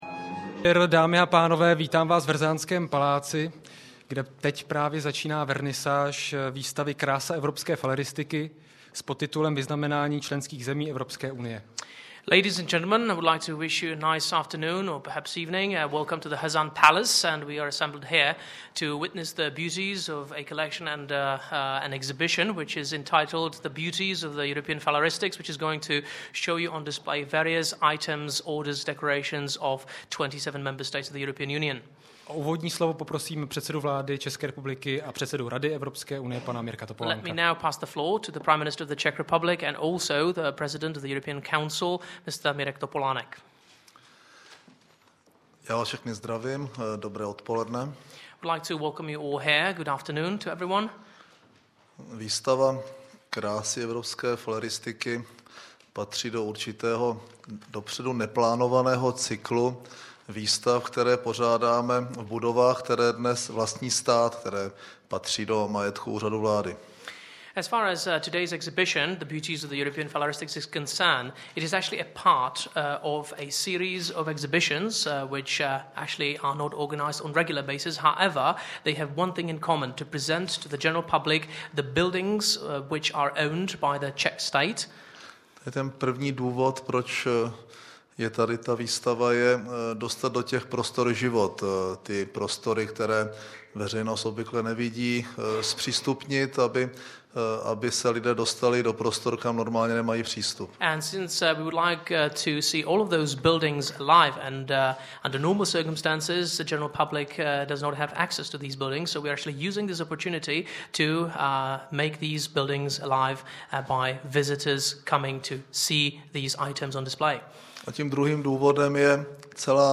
Projev při příležitosti otevření výstavy Krása evropské faleristiky